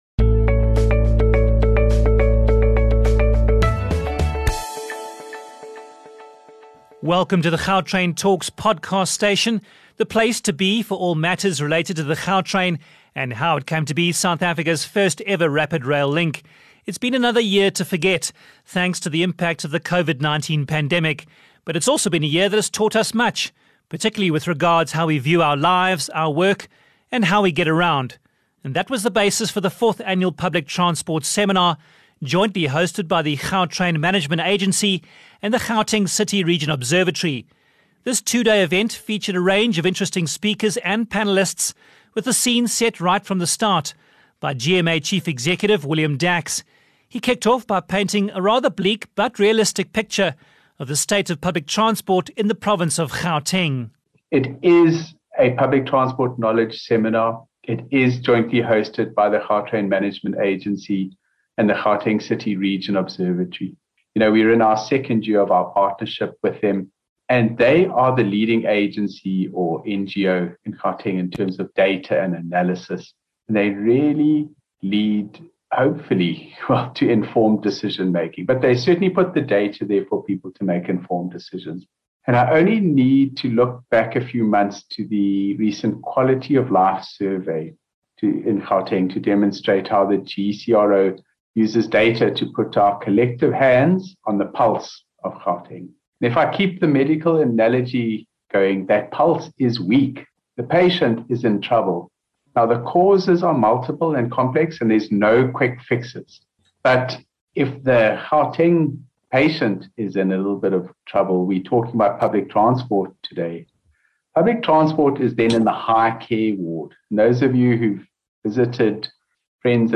The fourth annual Public Transport Seminar was jointly hosted by the Gautrain Management Agency and the Gauteng City Region Observatory